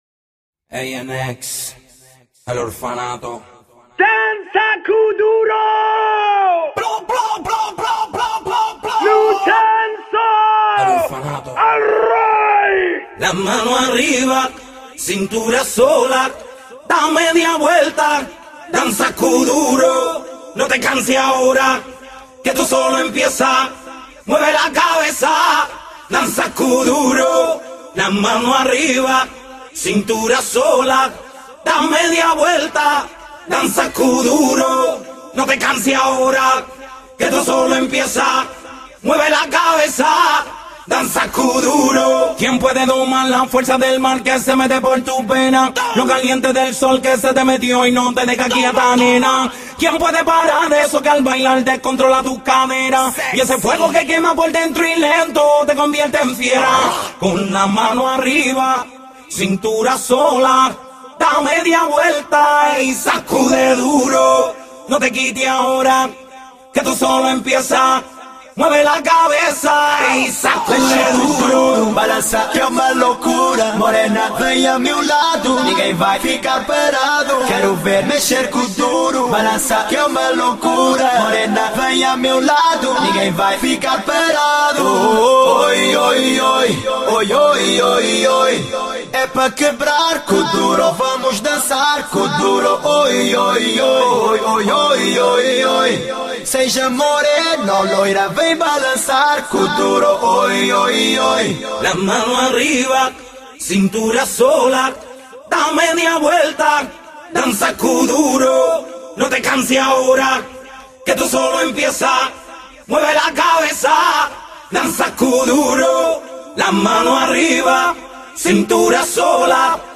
Официальная акапелла для вас!